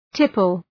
Shkrimi fonetik {‘tıpəl}